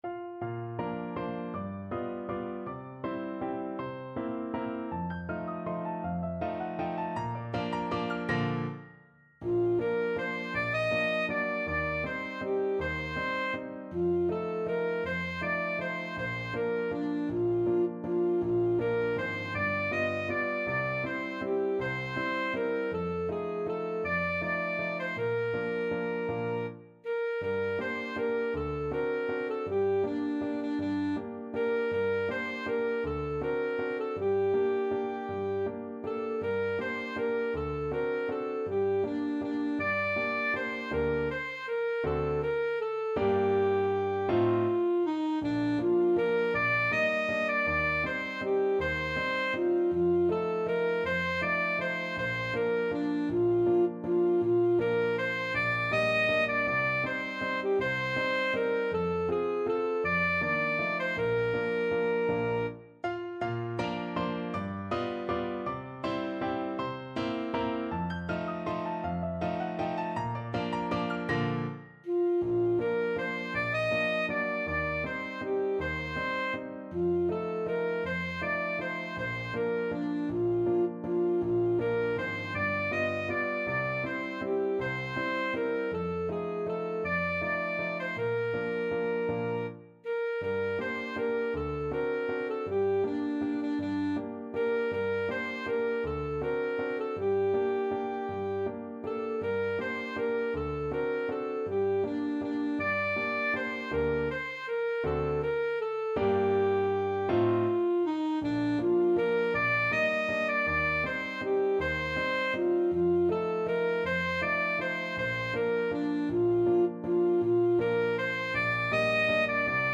Alto Saxophone
~ = 160 Tempo di Valse
3/4 (View more 3/4 Music)
Traditional (View more Traditional Saxophone Music)